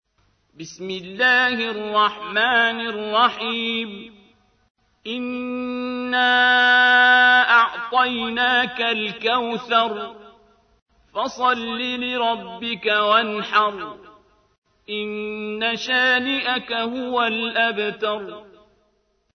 Audio icon ترتیل سوره کوثر با صدای عبدالباسط محمد عبدالصمد ازمصر (76.13 KB)